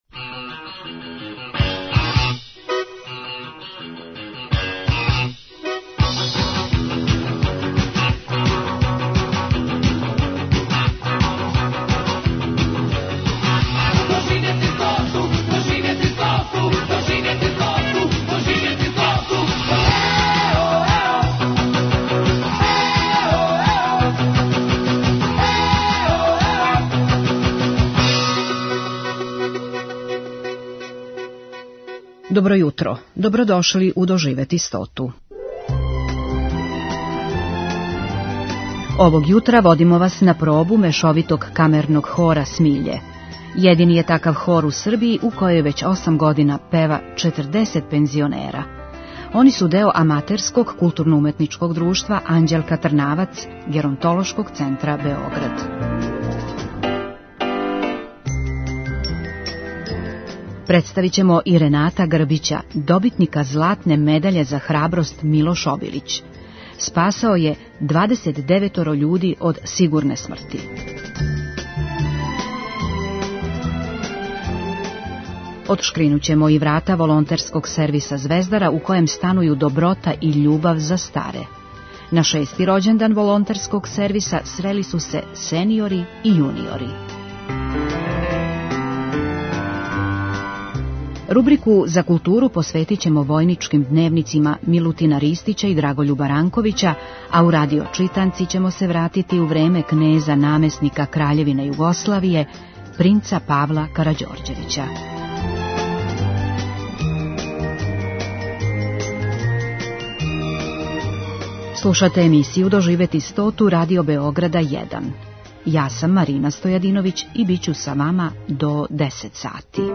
Овог јутра водимо вас на пробу Мешовитог камерног хора "Смиље". Једини је такав хор у Србији у којем већ осам година пева 40 пензионера из 19 певачких група.
Емисија "Доживети стоту" Првог програма Радио Београда већ двадесет четири године доноси интервјуе и репортаже посвећене старијој популацији.